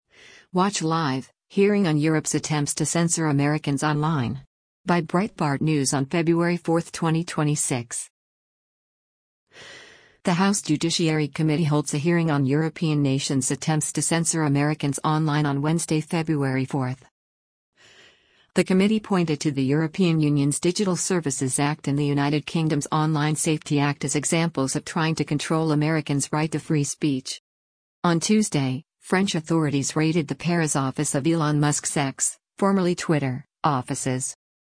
The House Judiciary Committee holds a hearing on European nations’ attempts to censor Americans online on Wednesday, February 4.